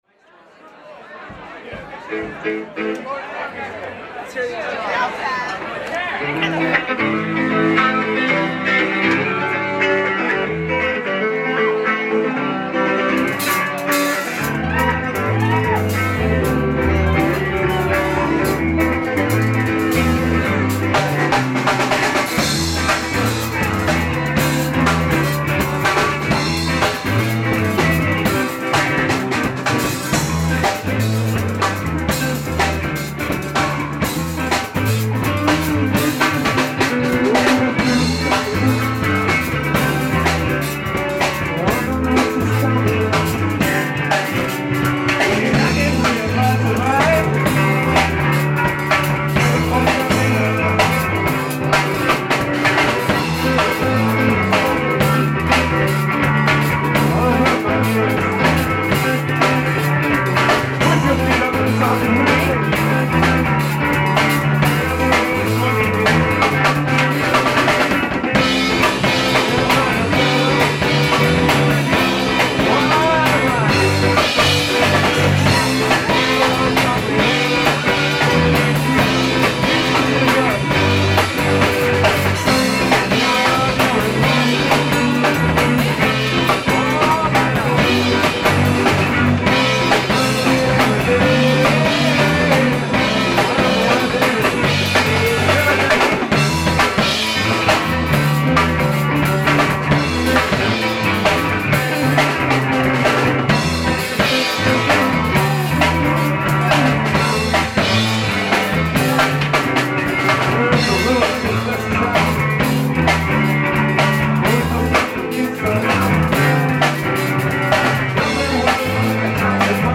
Bass
Lead Guitar, Vocals
Drums
Live at the Underground (10/13/95) [Entire Show]